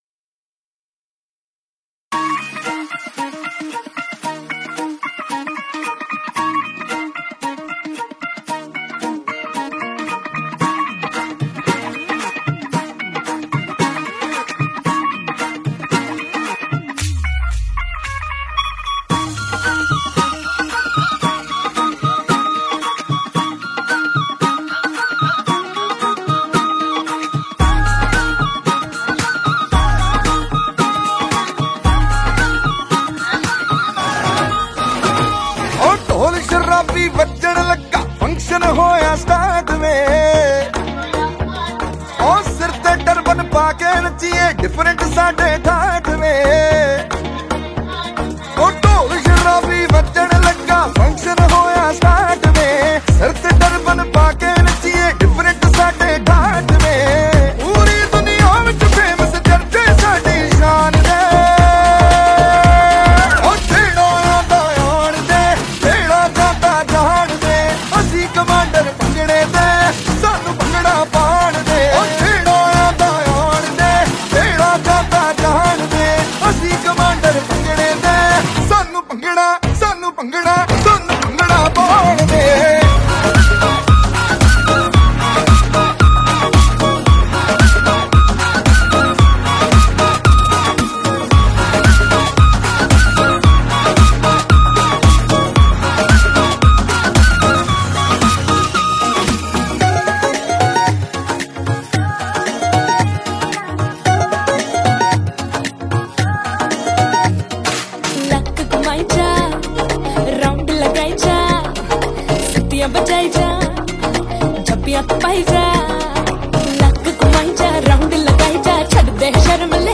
Category New Punjabi Song 2023 Singer(s